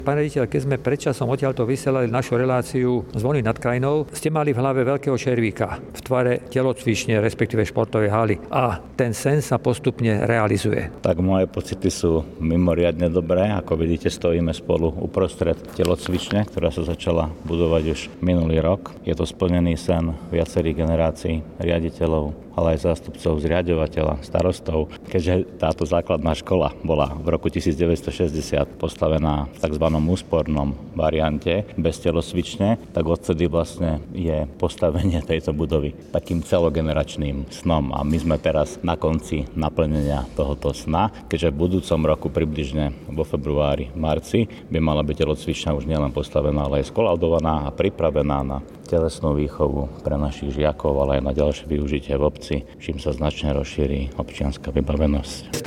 REPORT�� V R�DIU REGINA - SEPTEMBER 2024